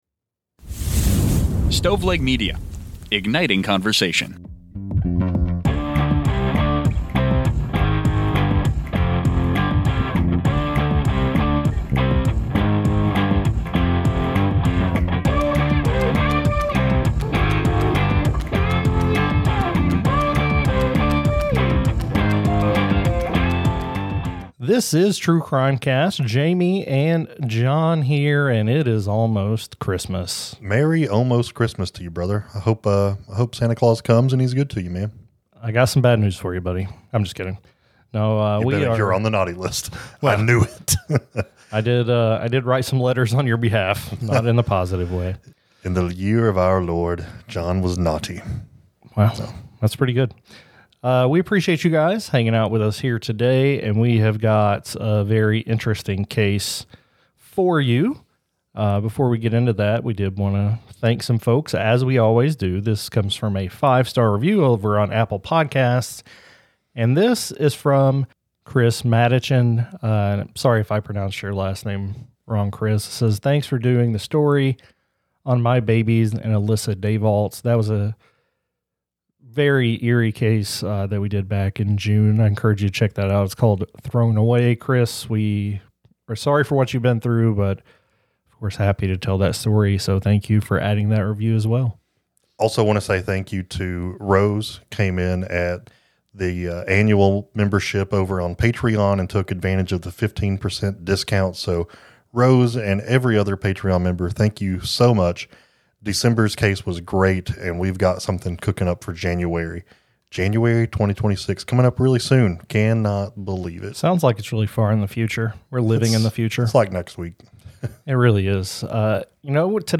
True Crime, Society & Culture, Documentary, Personal Journals